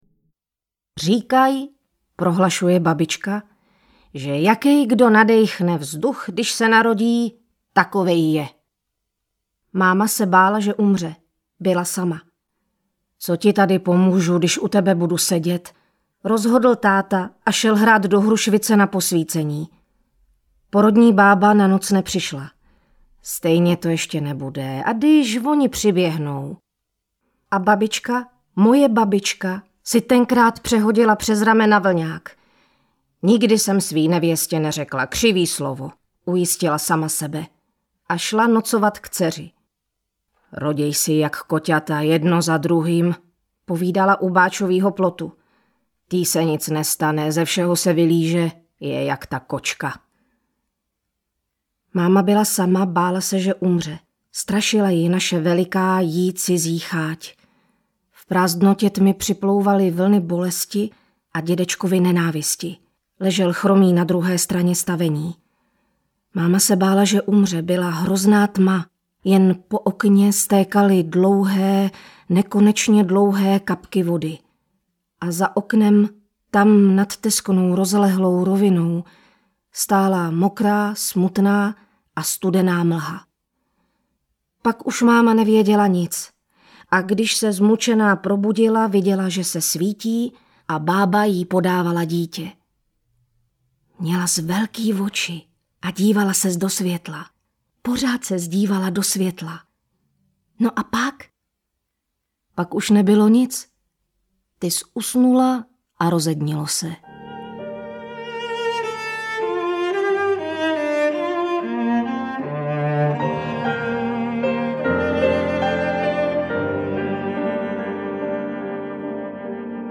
Strhující a bohužel tolik aktuální nahrávka z archivu Českého rozhlasu vychází zároveň jako vzpomínka na herečku Janu Frankovou (1967-2021).
Audiokniha
Čte: Jana Fraňková